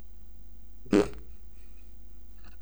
pet.wav